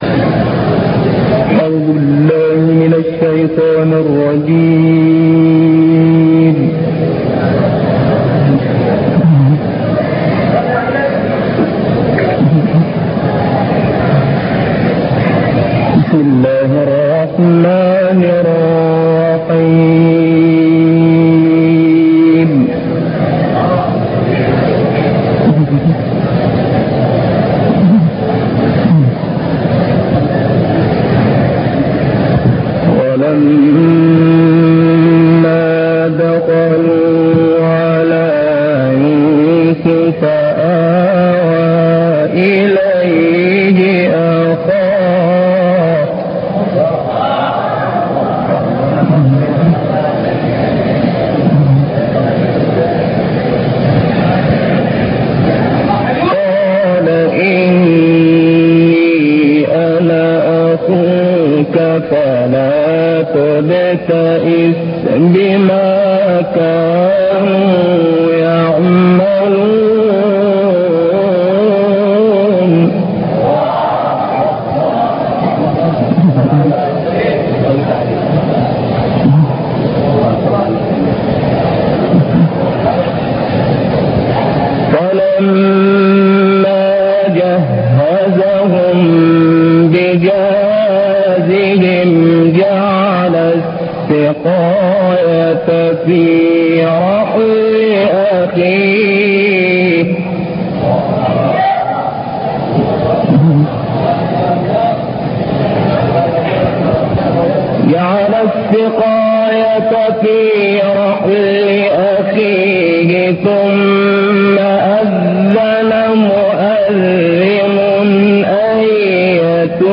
تسجيلات خارجية